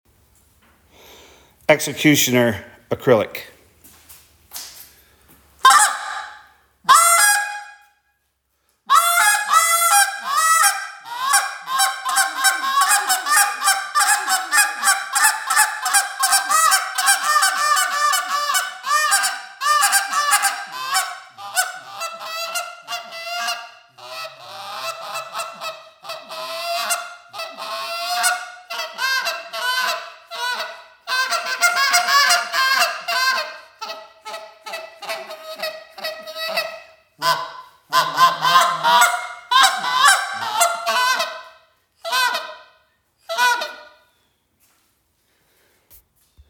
Canada Goose Call
Engineered for versatility, this mid-range call provides exceptional power and speed across its entire vocal spectrum, from the quietest low-end finishing notes to loud, aggressive come back calls.
• Mid-range Canada goose call
• Power on low end as well as high end